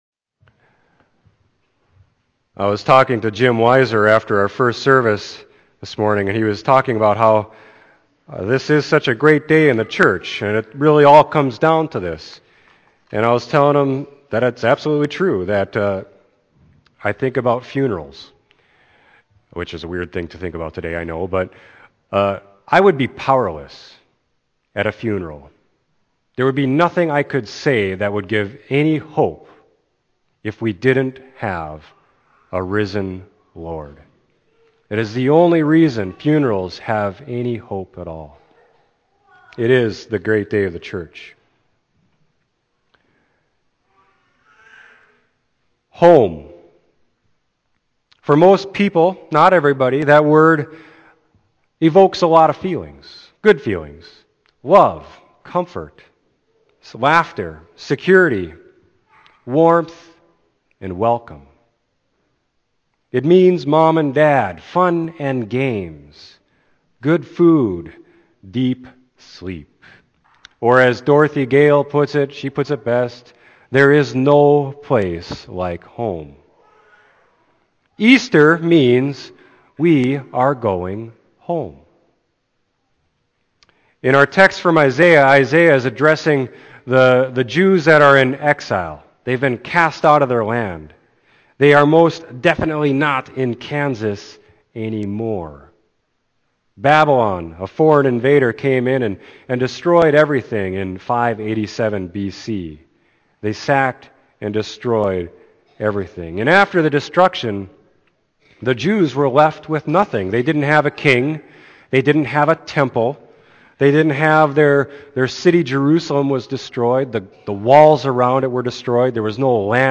Sermon: Isaiah 55.6-13